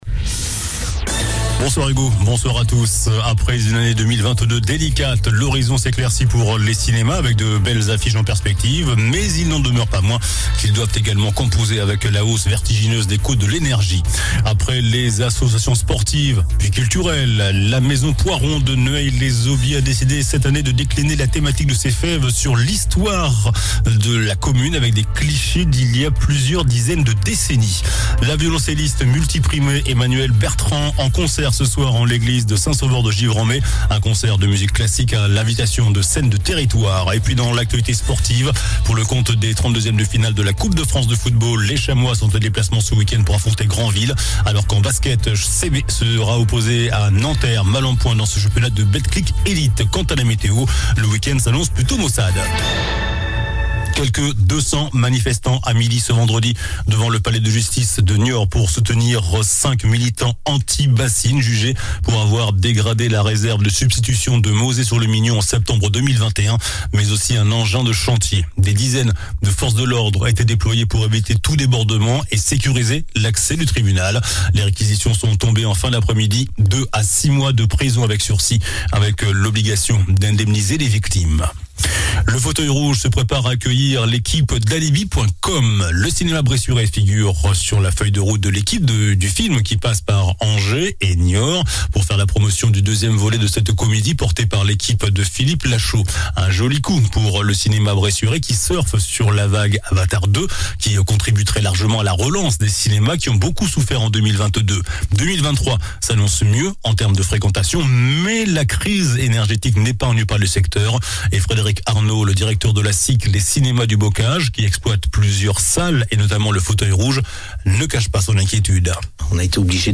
JOURNAL DU VENDREDI 06 JANVIER ( SOIR )